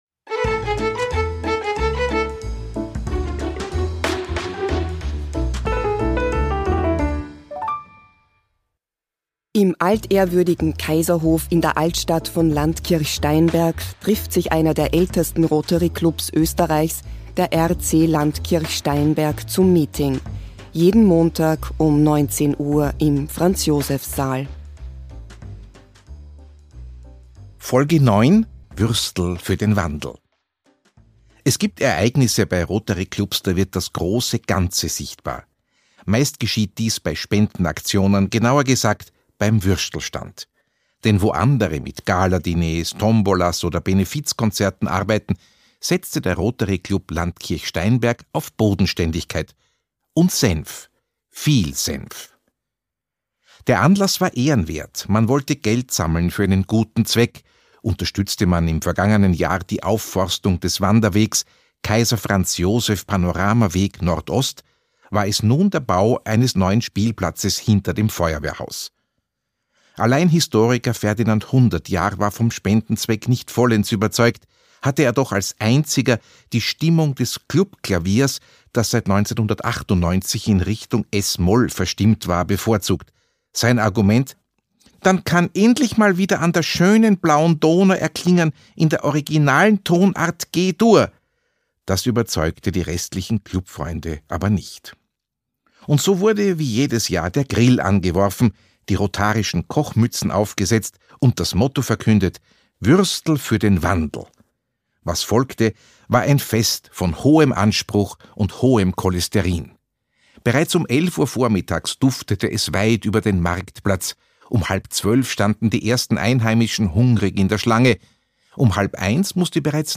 Audio-Comedy